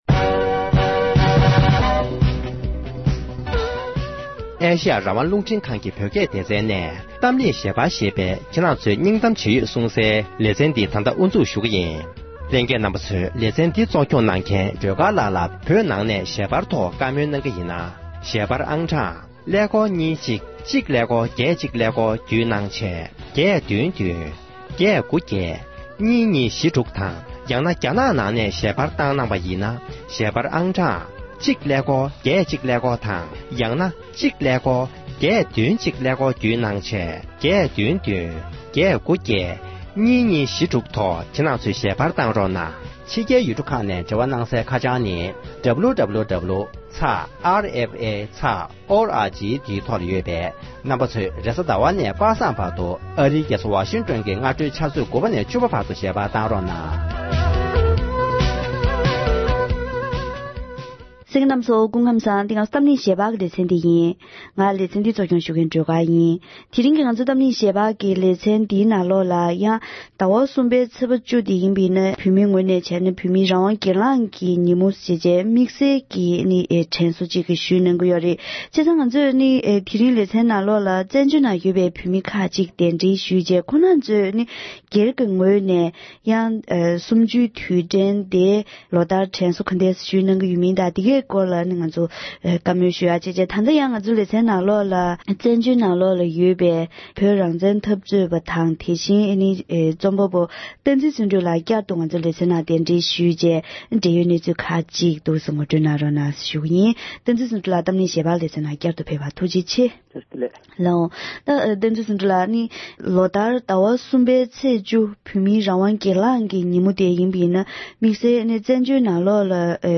བོད་མིའི་རང་དབང་སྒེར་ལངས་ཀྱི་ཉིན་མོར་བཙན་བྱོལ་ནང་ཡོད་པའི་བོད་མི་ཚོས་དྲན་གསོ་ཇི་ལྟར་གནང་བའི་ཐད་བཀའ་མོལ་ཞུས་པ།